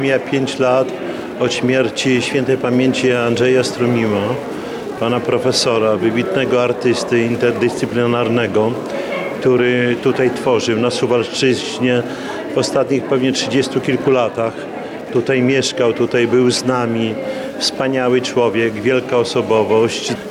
Zebranych w galerii przywitał Czesław Renkiewicz, prezydent Suwałk.